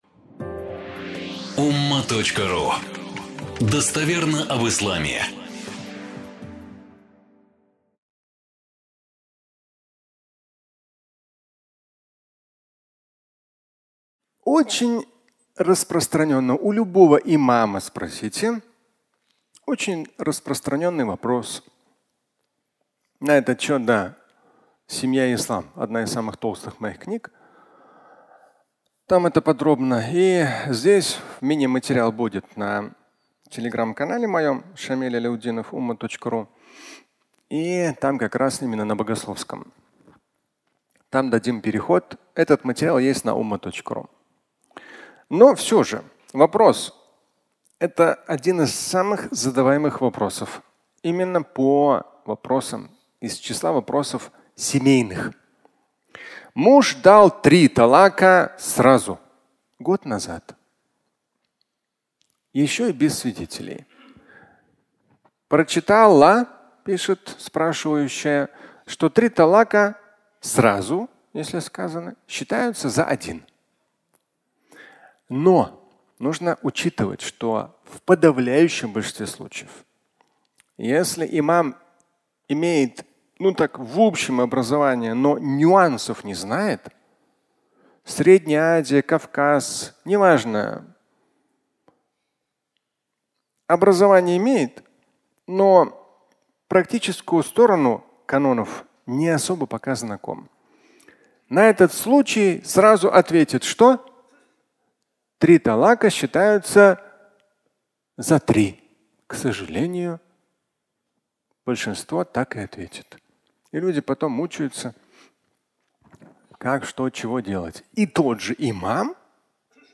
Тройной талак (аудиолекция)